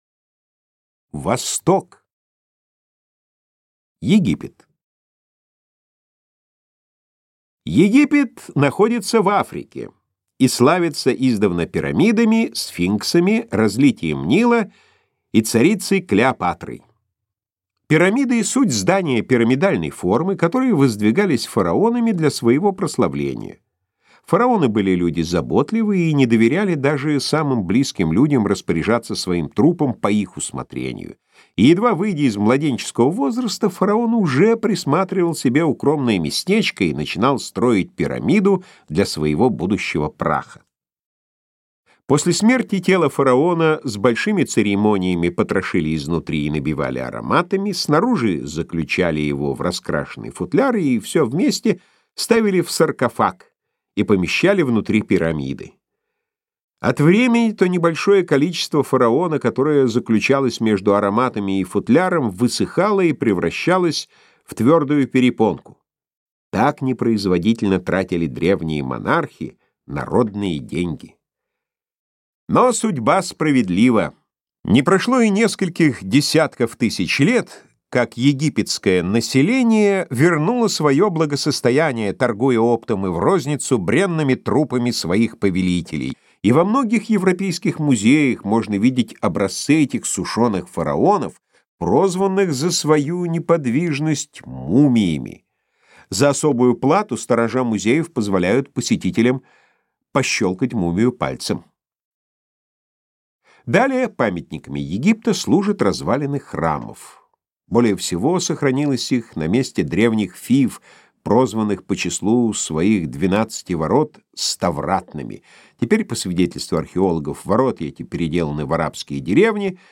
Аудиокнига Всеобщая история, обработанная «Сатириконом» | Библиотека аудиокниг